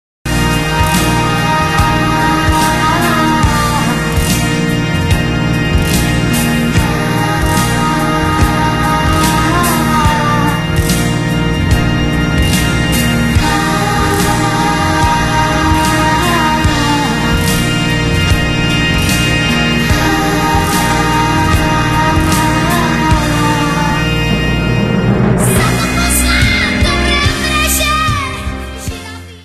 This is a sound sample from a commercial recording.